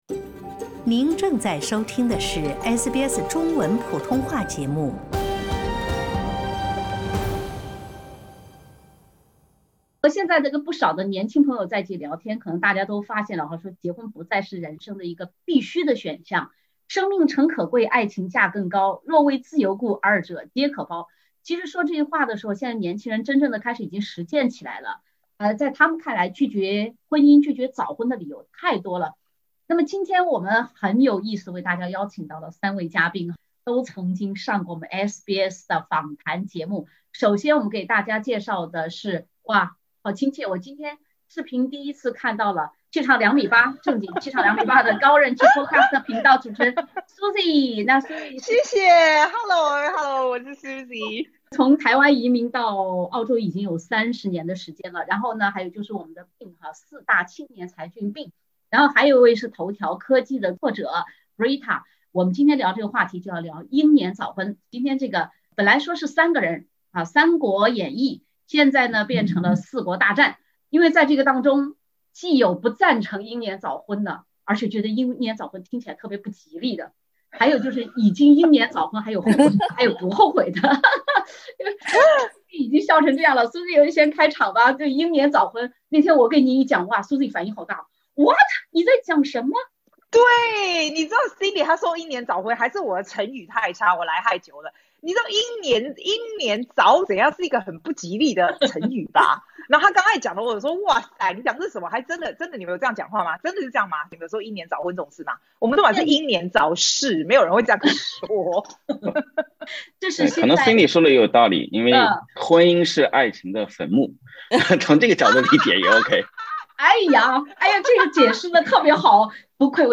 SBS全新談話類節目《對話後浪》，傾聽普通人的煩惱，了解普通人的歡樂，走進普通人的生活。
歡迎收聽澳大利亞最親民的中文聊天類節目-《對話後浪》。